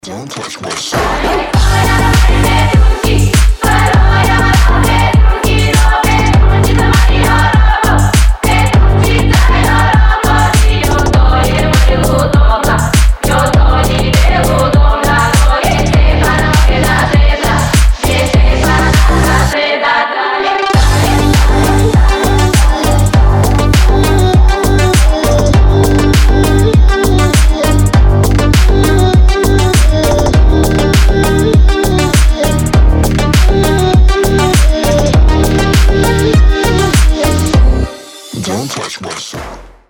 Рингтоны ремиксы , Рингтоны техно
Deep house
G-house
Brazilian bass , Мощные басы